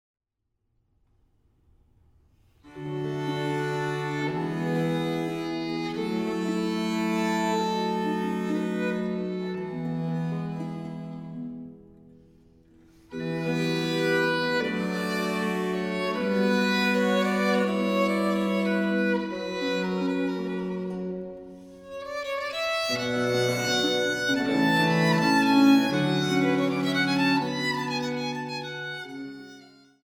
für VI, VdG & Bc